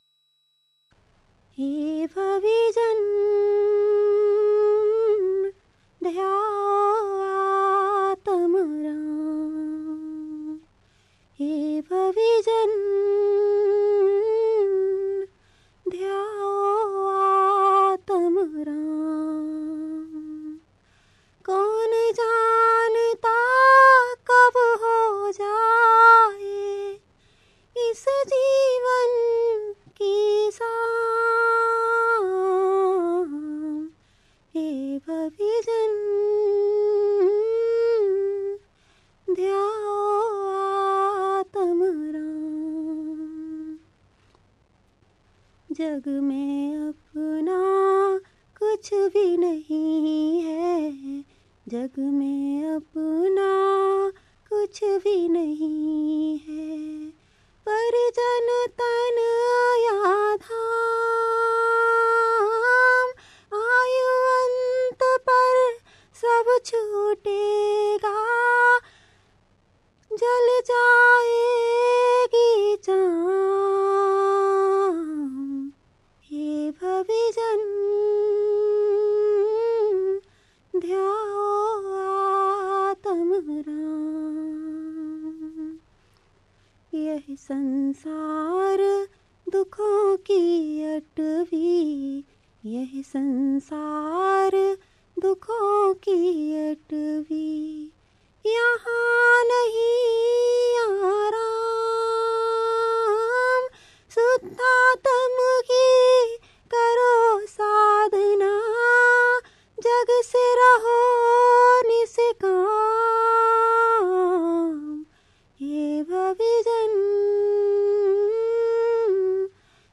भजन 16